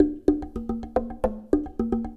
APH SLIT DRM.wav